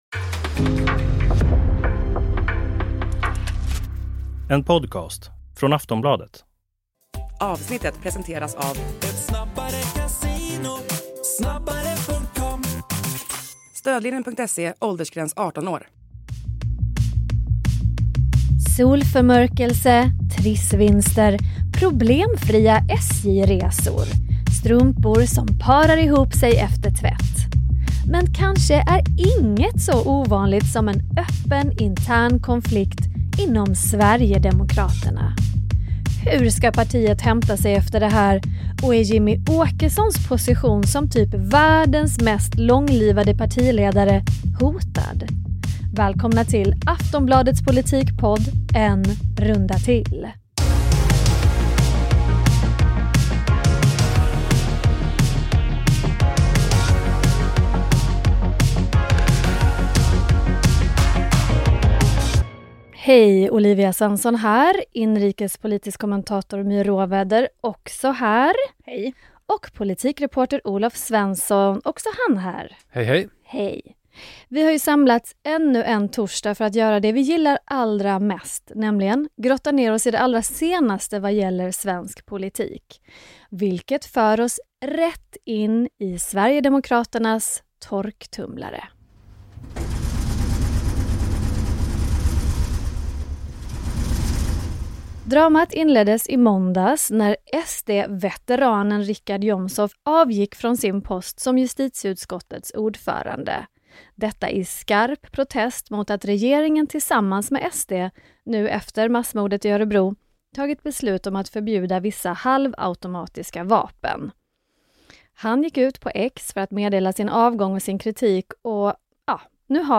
Klipp från SVT och regeringskansliet.